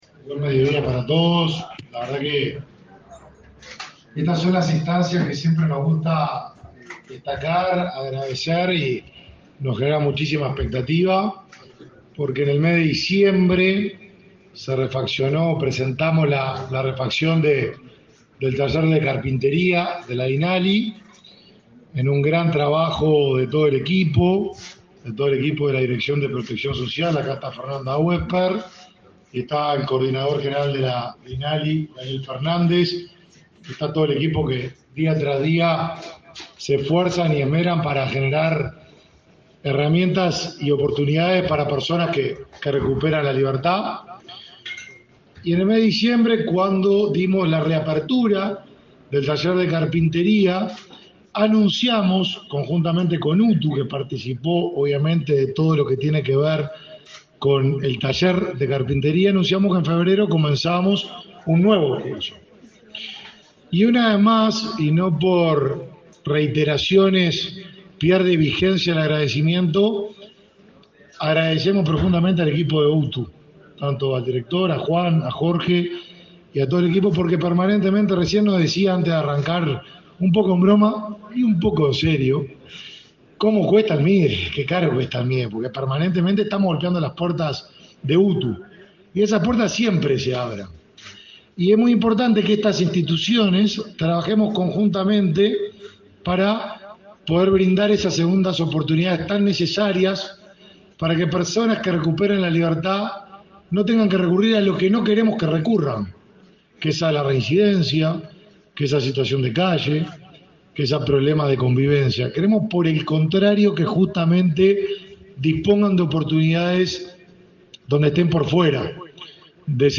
Conferencia de prensa por inicio de cursos de carpintería en los talleres de la Dinali
En la oportunidad, se expresaron el ministro de Desarrollo Social, Martín Lema, y el director general de UTU, Juan Pereyra.